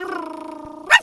dog_2_small_bark_01.wav